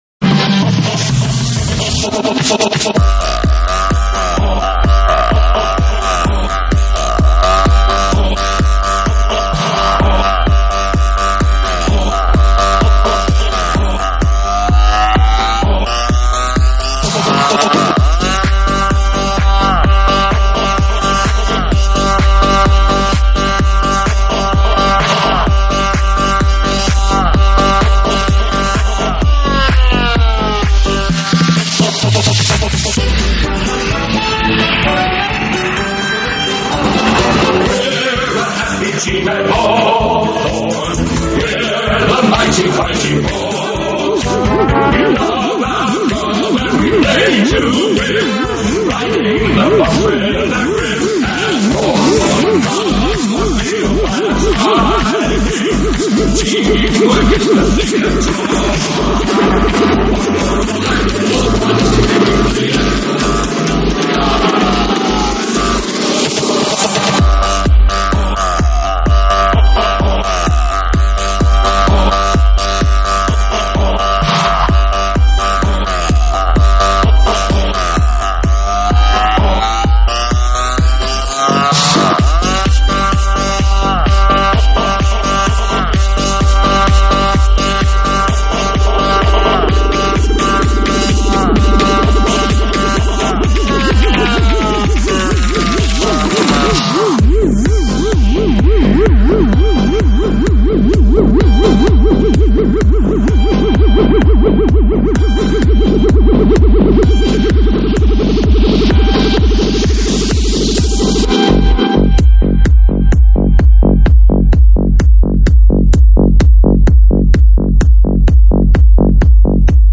舞曲类别：3D全景环绕